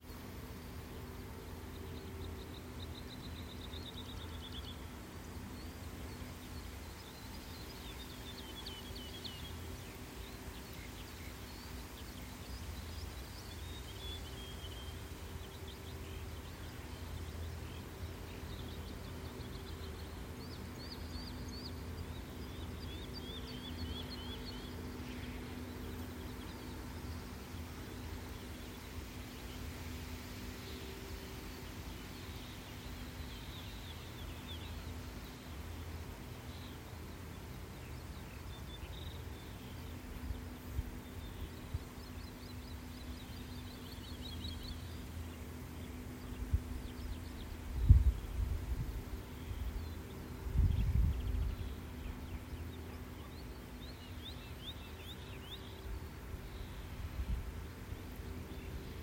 Sila cīrulis, Lullula arborea
Administratīvā teritorijaAlūksnes novads
StatussDzied ligzdošanai piemērotā biotopā (D)